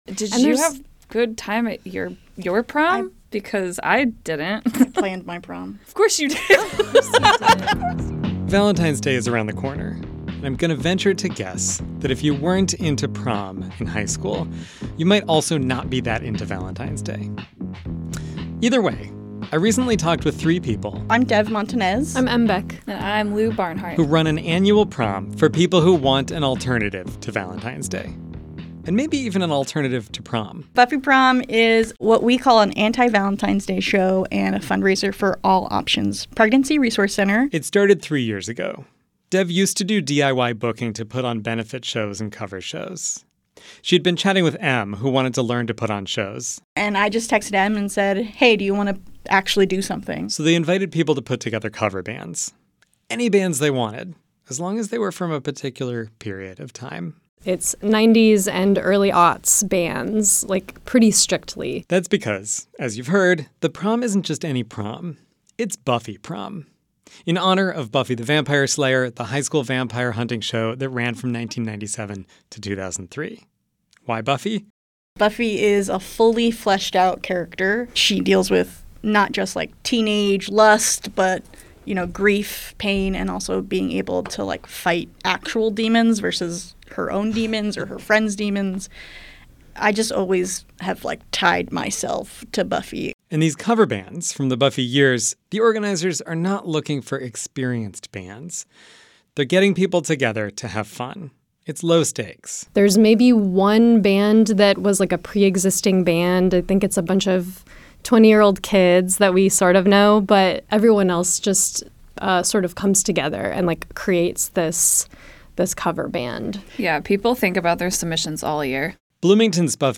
Arts and culture news, events, interviews, and features from around southern and central Indiana.